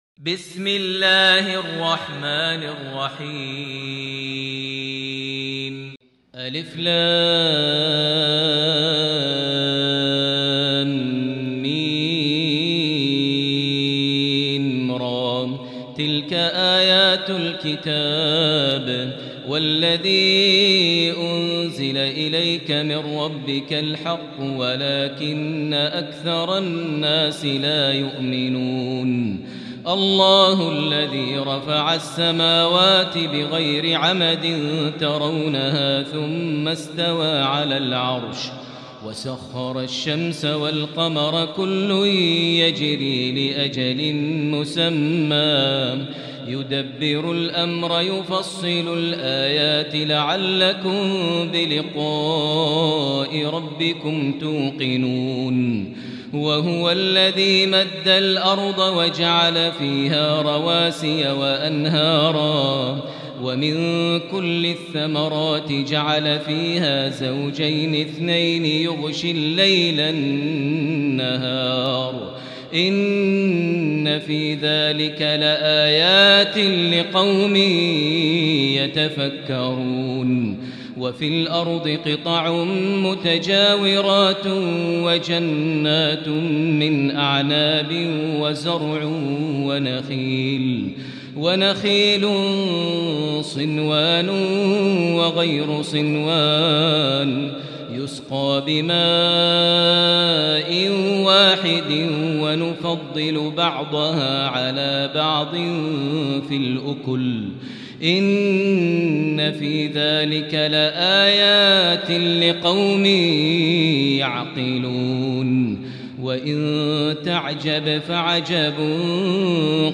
سورة الرعد النسخة الثانية > مصحف الشيخ ماهر المعيقلي (2) > المصحف - تلاوات ماهر المعيقلي